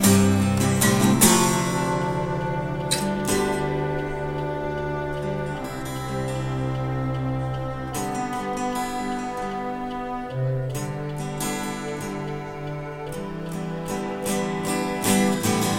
声道立体声
等到明天部分结束吉他
Tag: 152 bpm Acoustic Loops Guitar Acoustic Loops 2.66 MB wav Key : Unknown